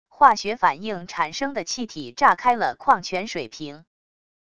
化学反应产生的气体炸开了矿泉水瓶wav音频